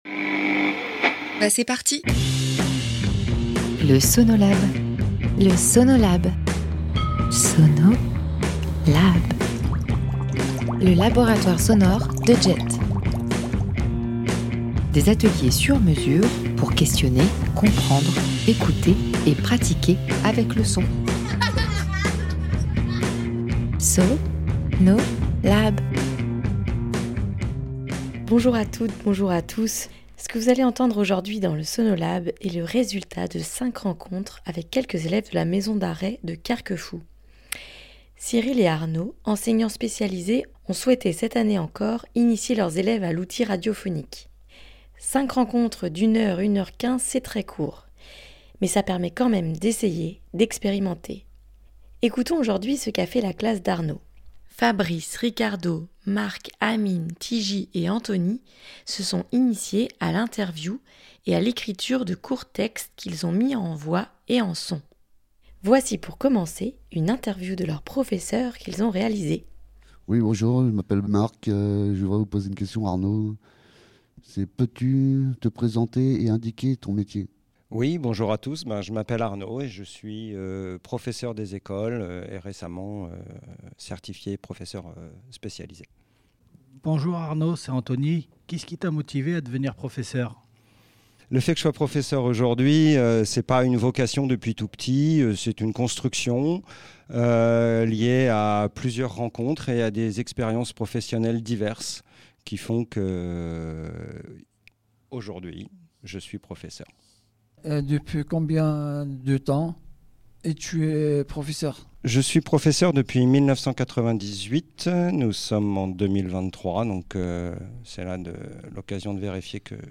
interview
professeur des écoles et de courts textes mis en voix et sons par les détenus participants.